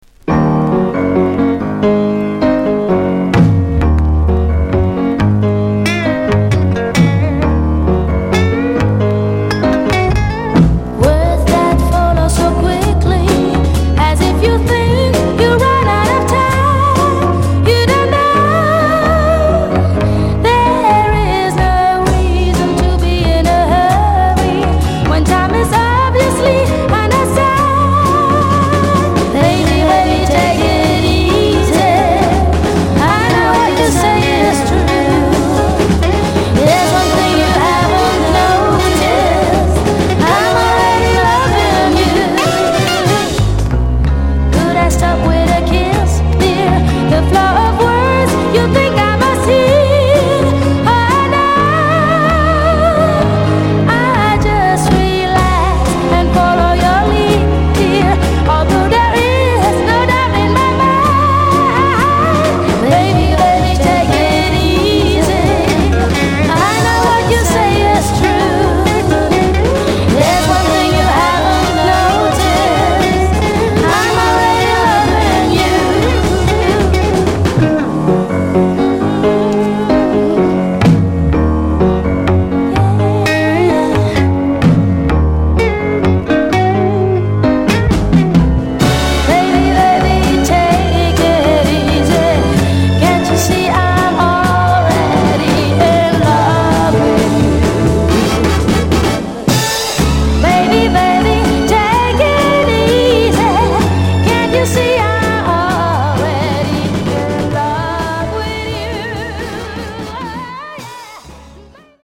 Soft Rock spain
美しく伸びやかで、しっかりソウルも感じさせる歌声が癖になります。